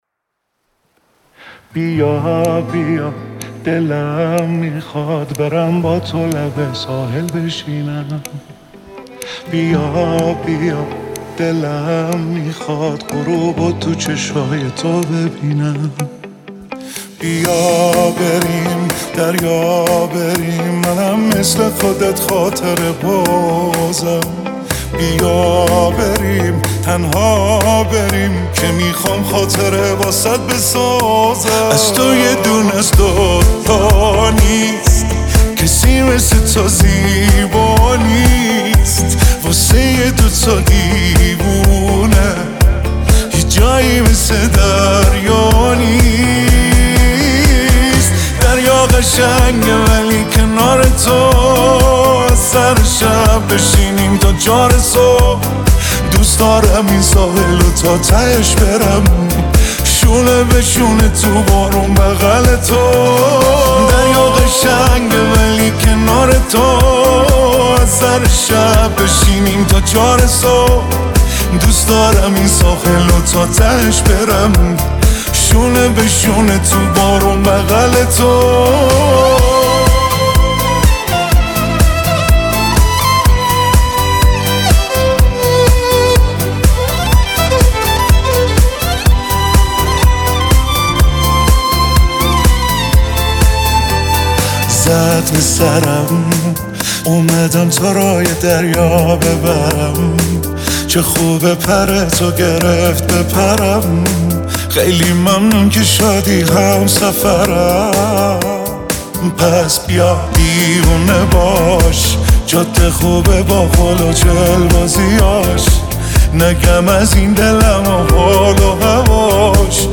رپ
اهنگ ایرانی